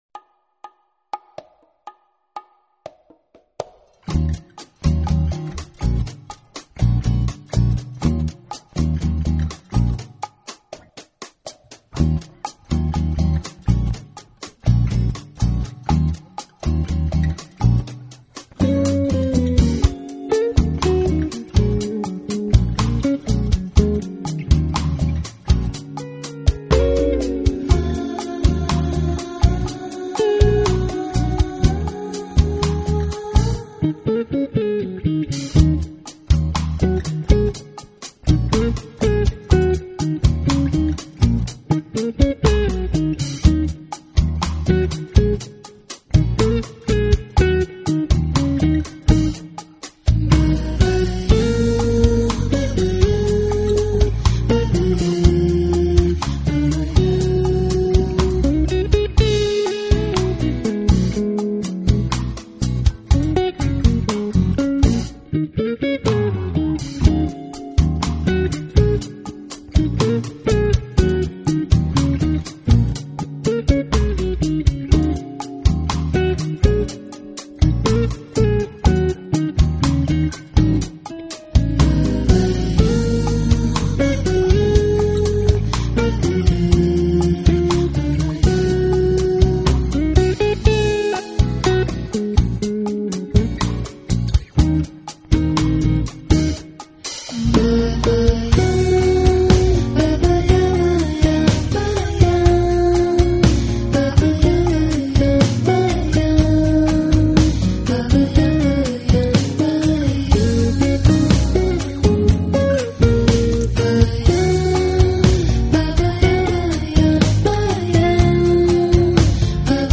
音乐风格: Smooth Jazz